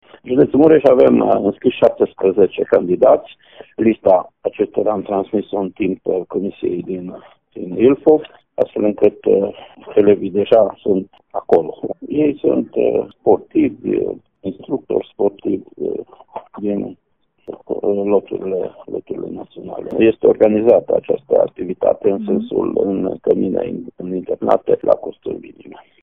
Inspectorul școlar general al județului Mureș, Ștefan Someșan.